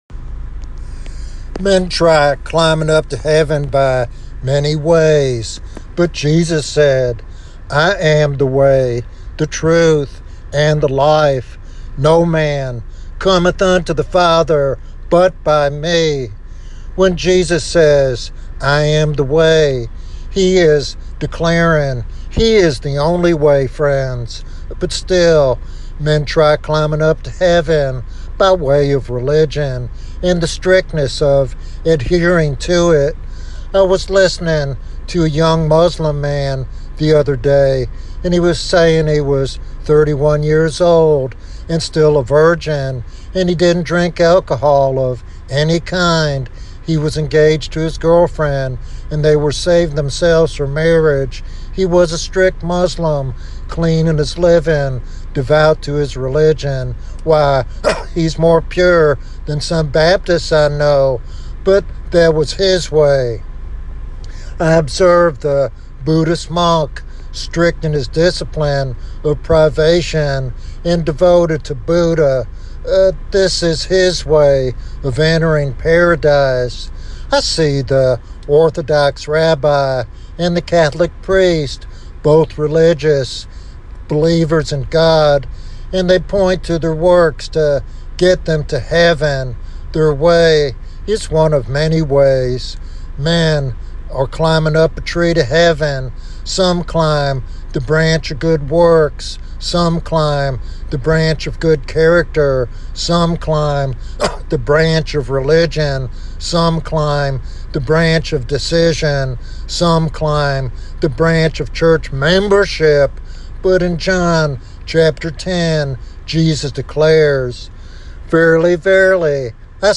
This sermon is a compelling invitation to experience the life-changing grace of Jesus.